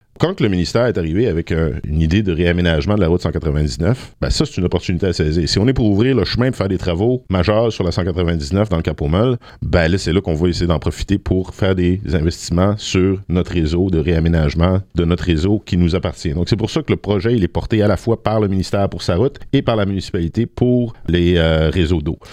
Le maire Valiquette affirme qu’il y a une opportunité à saisir pour améliorer les infrastructures publiques, telles que le réseau d’aqueduc.